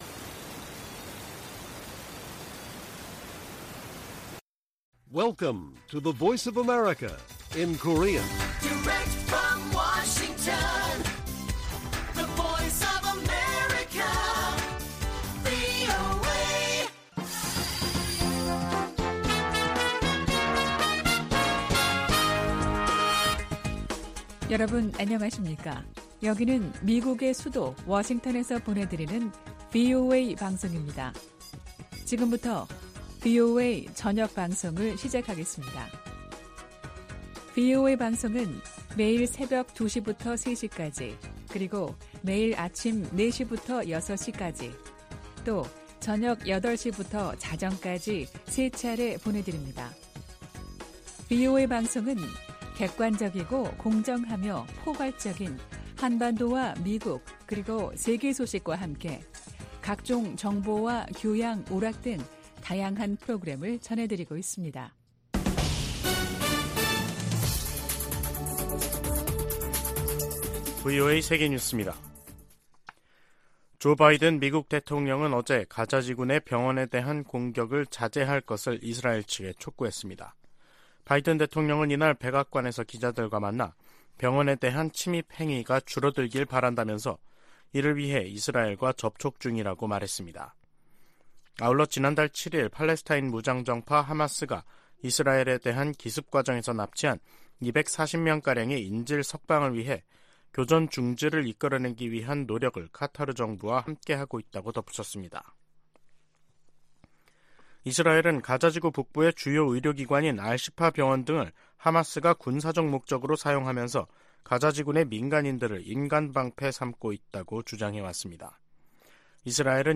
VOA 한국어 간판 뉴스 프로그램 '뉴스 투데이', 2023년 11월 14일 1부 방송입니다. 한국을 방문한 로이드 오스틴 미 국방장관은 한반도 평화와 안정에 대한 유엔군사령부의 약속은 여전히 중요하다고 강조했습니다. 미 국무부는 이번 주 열리는 아시아태평양 경제협력체(APEC) 회의를 통해 내년도 역내 협력을 위한 전략적 비전이 수립될 것이라고 밝혔습니다. 15일 미중정상회담에서 양자 현안뿐 아니라 다양한 국제 문제들이 논의될 것이라고 백악관이 밝혔습니다.